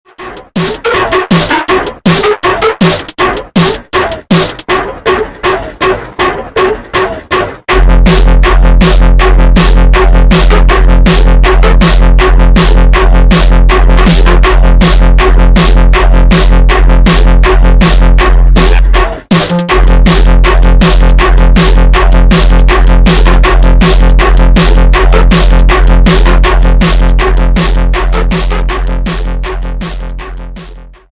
зарубежные без слов тикток клубные с басами